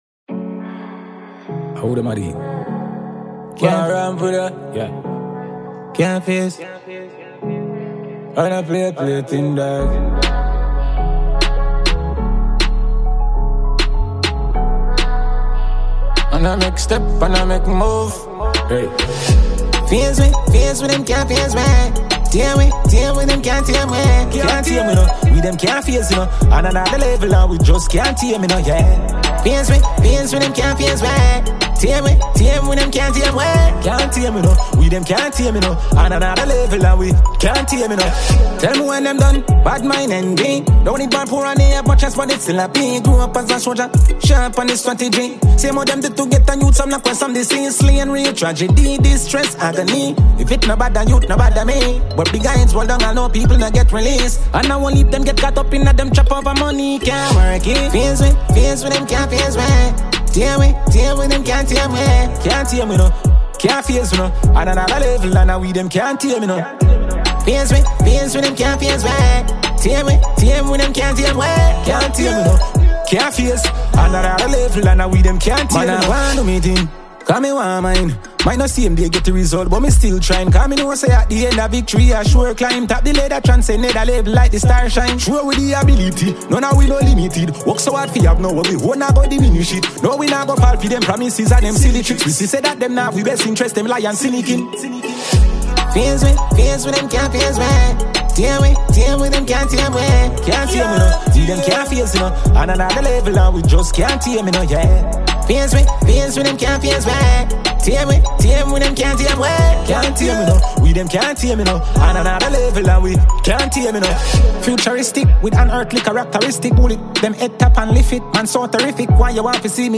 Jamaican dancehall musician